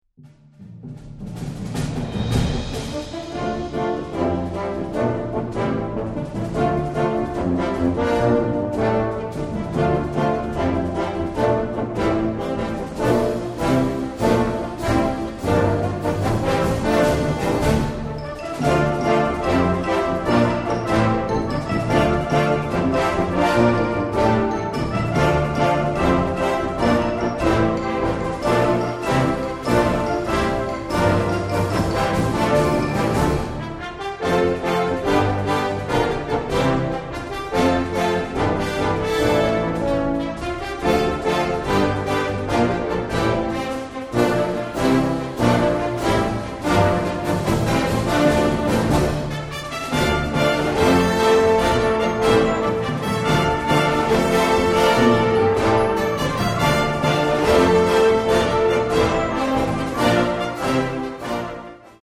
concert overture